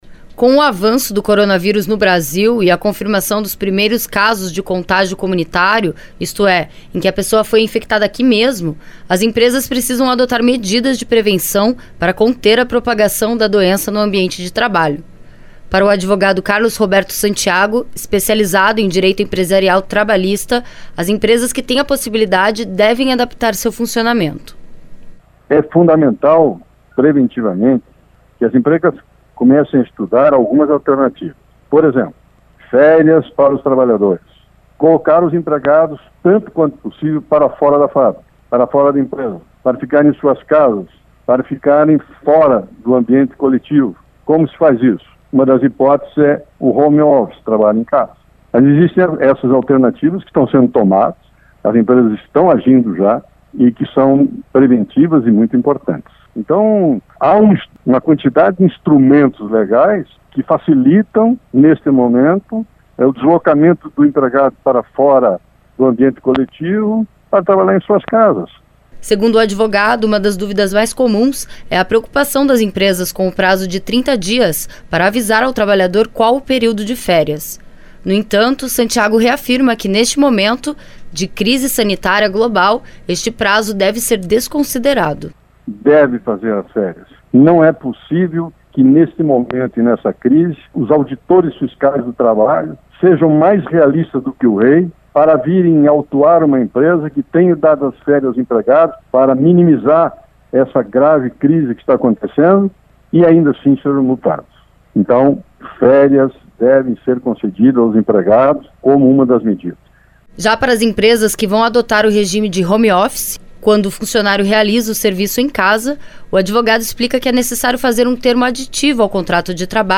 Repórter